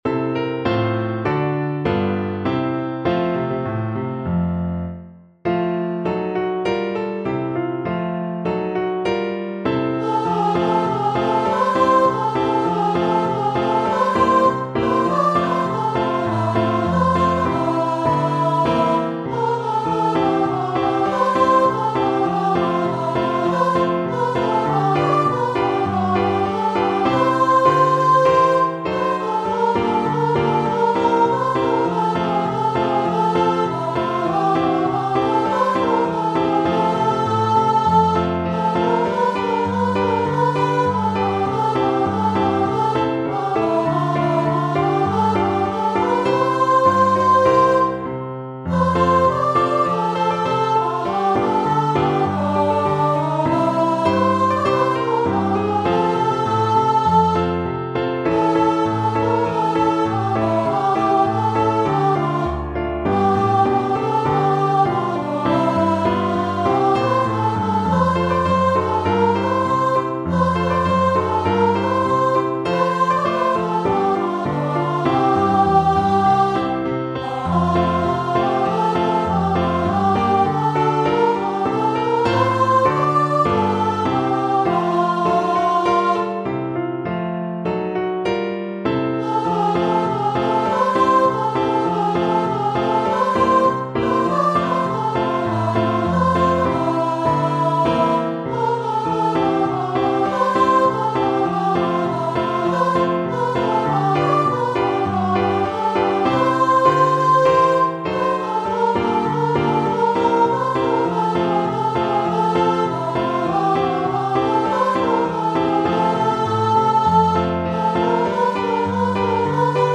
Voice
2/2 (View more 2/2 Music)
C5-D6
F major (Sounding Pitch) (View more F major Music for Voice )
Moderato = c.100
Classical (View more Classical Voice Music)